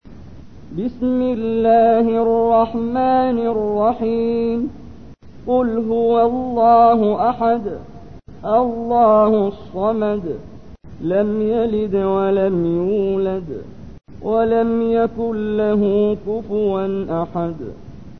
تحميل : 112. سورة الإخلاص / القارئ محمد جبريل / القرآن الكريم / موقع يا حسين